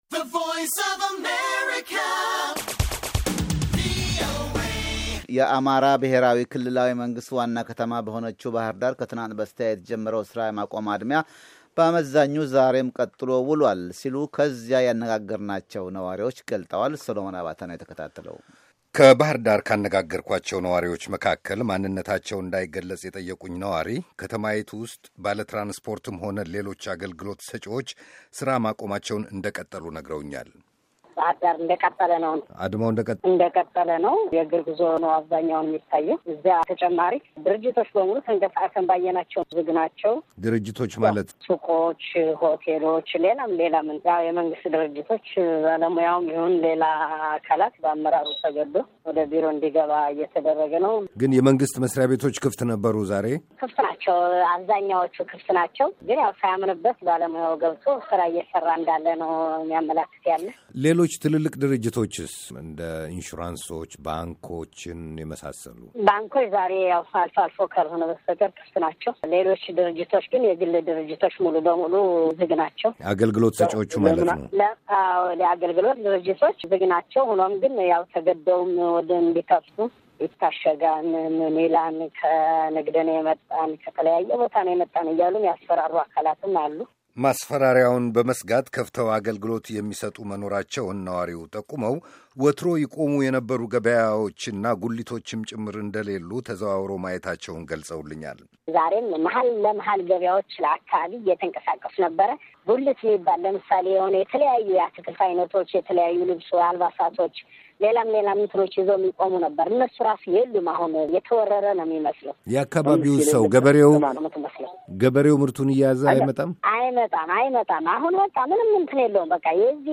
የአማራ ብሔራዊ ክልላዊ መንግሥት ዋና ከተማ በሆነችው ባሕር ዳር ከትናንት በስተያ የተጀመረው ሥራ የማቆም አድማ በአመዛኙ ዛሬም ቀጥሎ ውሏል ሲሉ ከዚያው ያነጋገርናቸው ነዋሪዎች ገልፀዋል፡፡